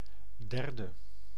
Ääntäminen
Ääntäminen Tuntematon aksentti: IPA: /ˈdɛr.də/ Haettu sana löytyi näillä lähdekielillä: hollanti Käännös Ääninäyte Substantiivit 1. third US 2. third party US UK Adjektiivit 3. third US Suku: n .